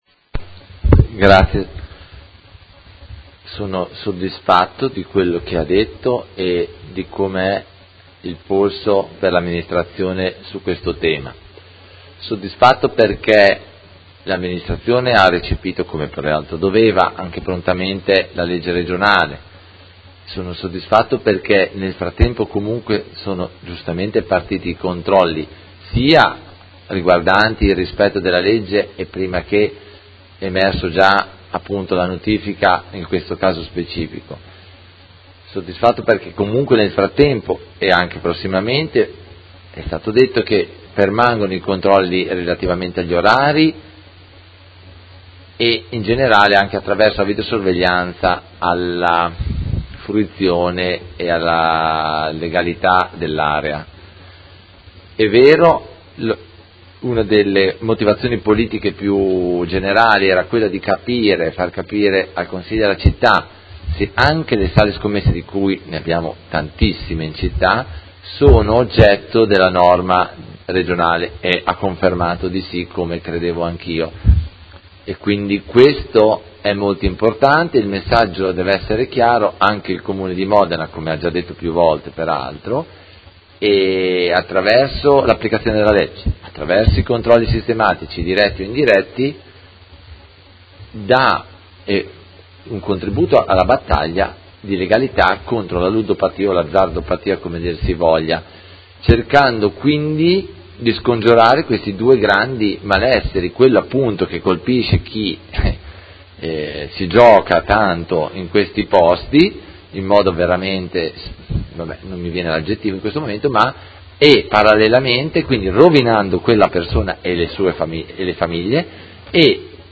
Seduta del 05/07/2018 Replica a risposta Assessore. Interrogazione del Consigliere Carpentieri (PD) avente per oggetto: Sala scommesse in Via Poletti, 78